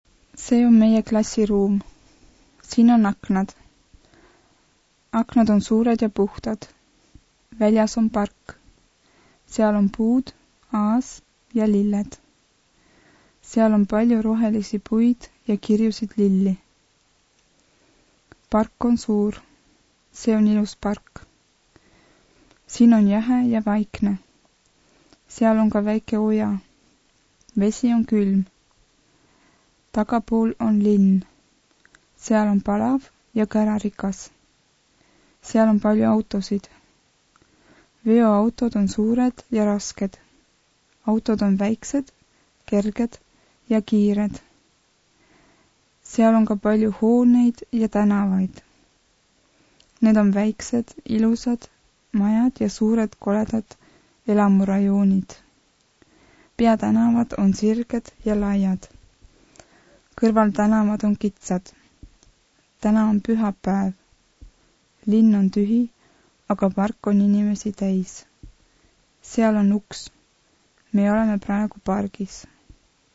Dies ist das normale Sprechtempo!